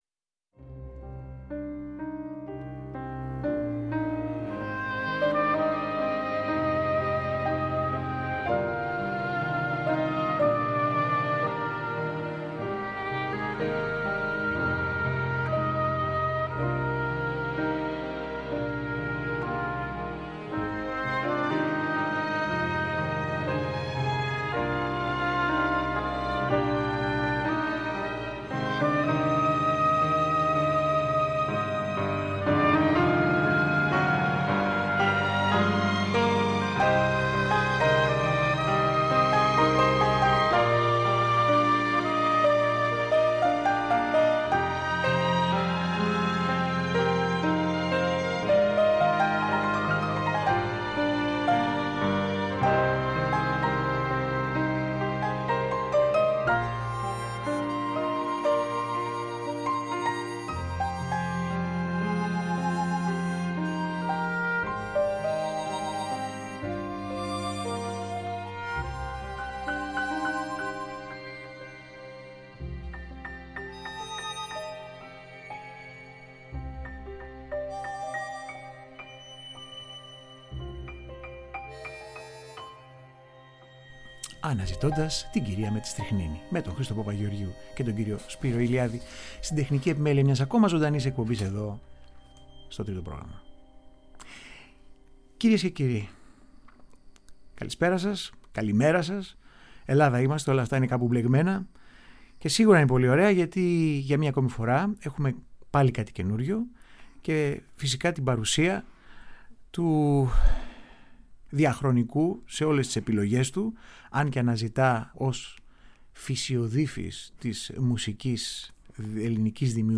Επιπλέον η εκπομπή πλαισιώνεται από άγνωστους χορούς του Πέτρου Πετρίδη και πολλές ιστορίες για τις πάντα περιπετειώδεις αναζητήσεις του ως φυσιοδίφης της ελληνικής δημιουργίας ανάμεσα στα σκονισμένα από την χρόνο και παραμελημένα από την επικαιρότητα κορυφαία έργα μιας άλλης εποχής.